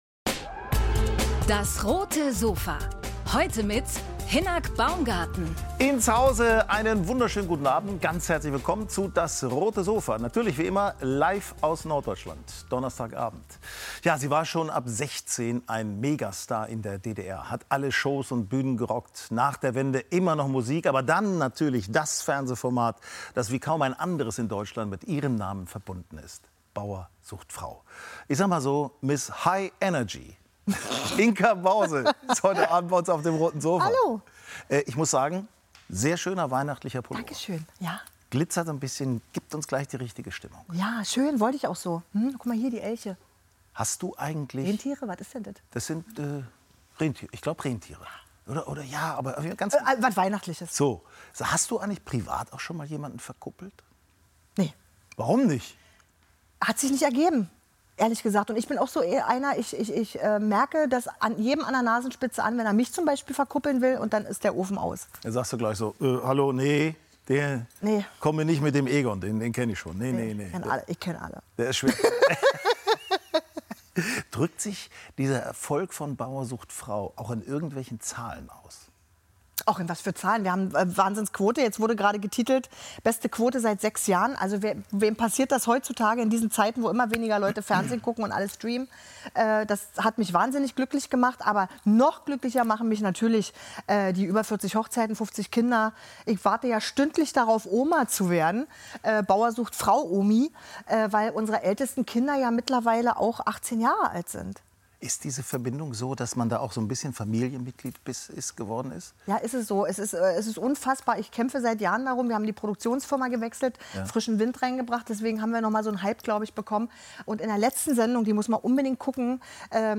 Moderatorin, Musikerin und Sängerin Inka Bause im Sofa-Talk ~ DAS! - täglich ein Interview Podcast